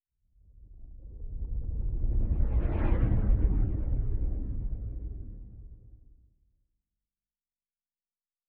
pgs/Assets/Audio/Sci-Fi Sounds/Movement/Distant Ship Pass By 4_6.wav at master
Distant Ship Pass By 4_6.wav